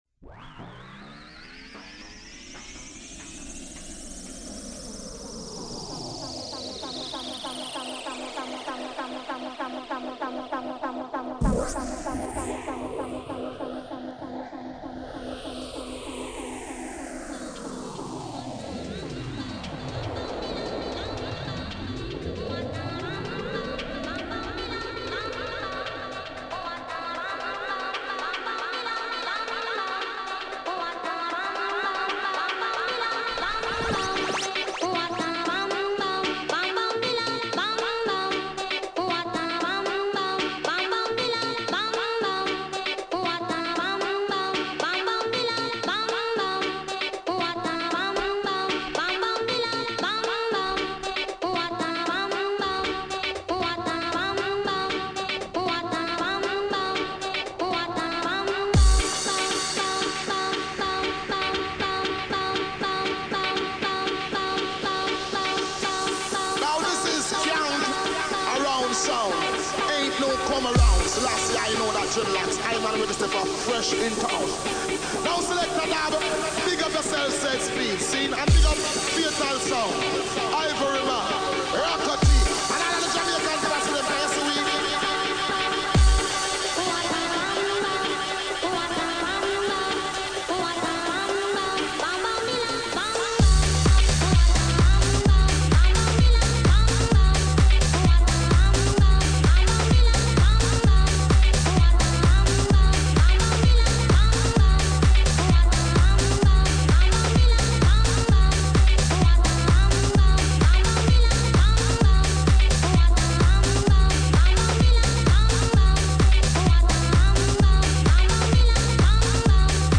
A funky house mix
Media: Vinyl Only